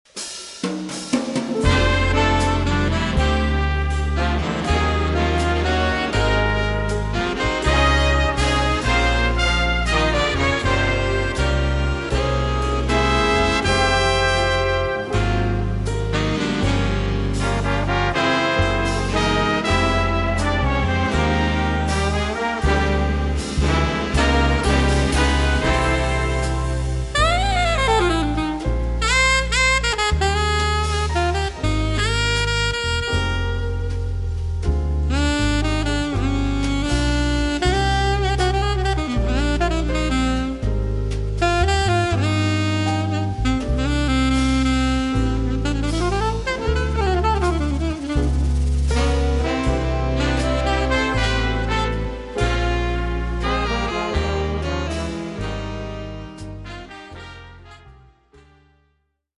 è il brano più soft dell'album
sax alto